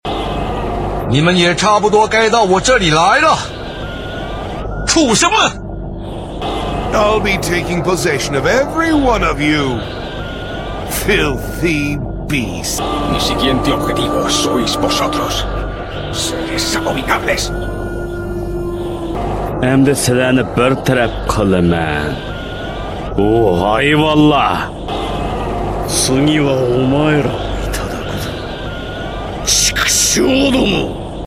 Naruto Shippuden Uchiha Madara dubbing